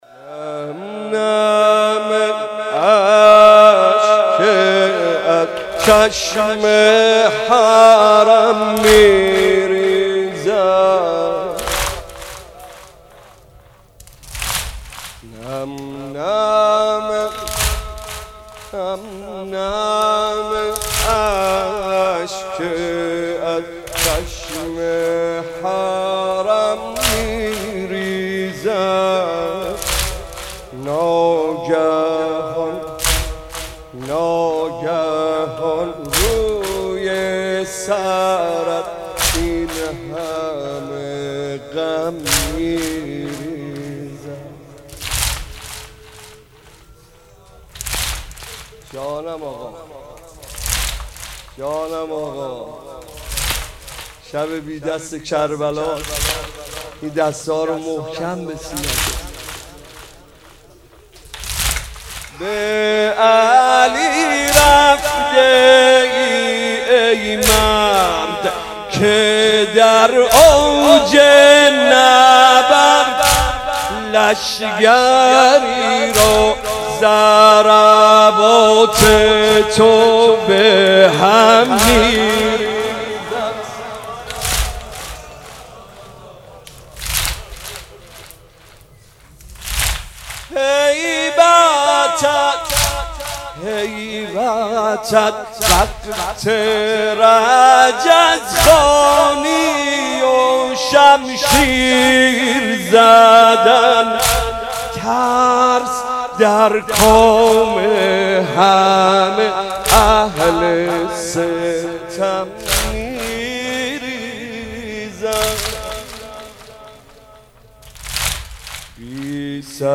مناسبت : تاسوعای حسینی